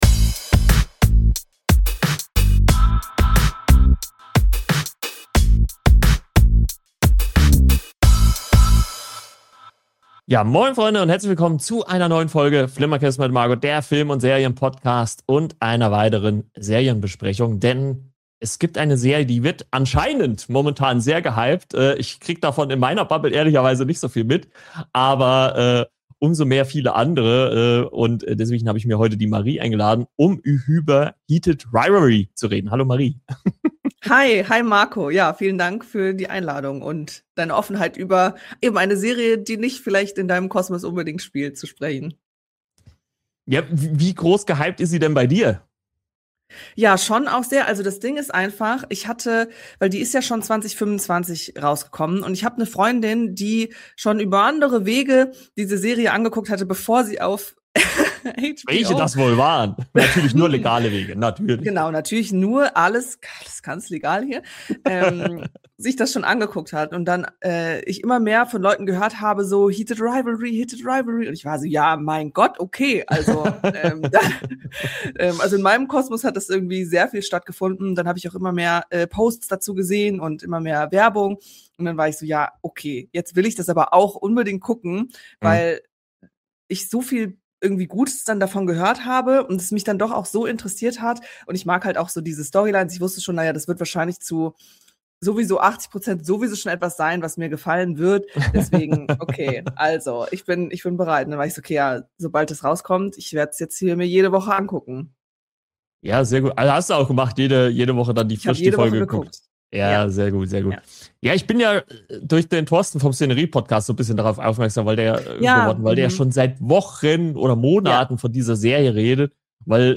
Eine unterhaltsame Diskussion zwischen Hype und Skepsis über eine Serie, die polarisiert.